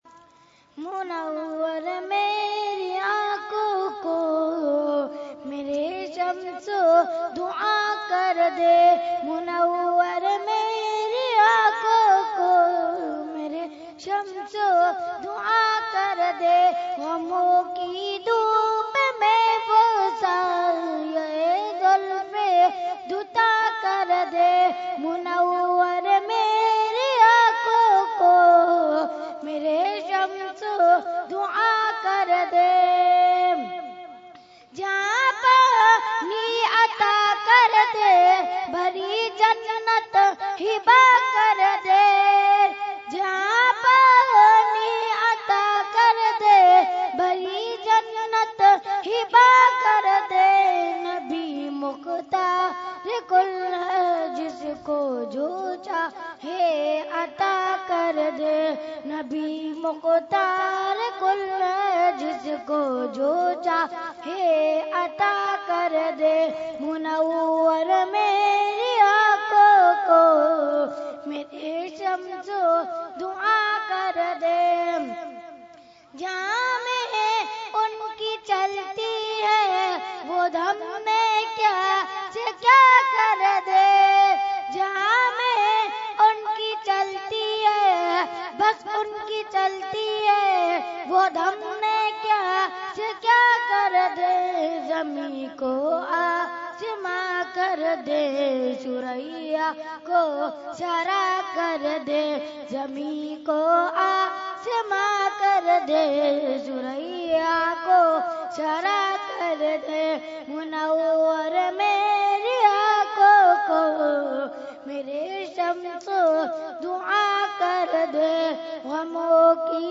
Category : Naat | Language : UrduEvent : Urs Ashraful Mashaikh 2016